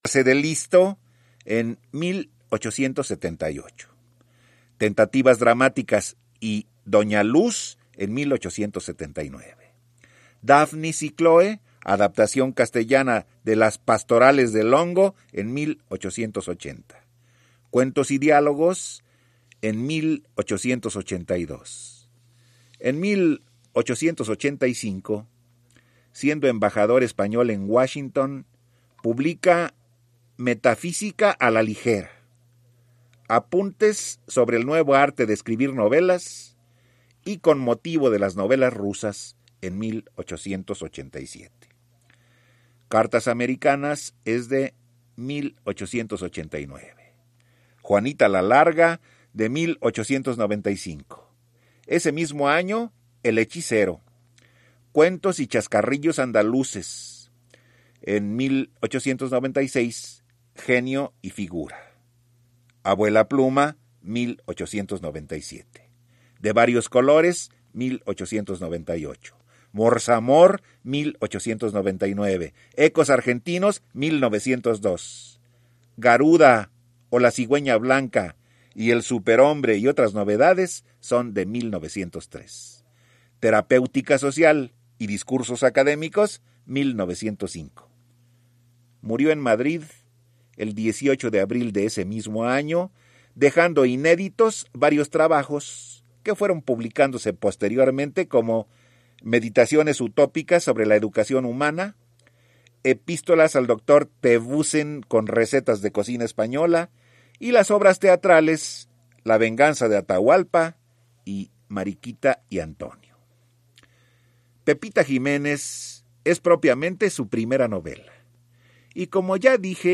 en el programa “Platícame un libro”, transmitido en 1988.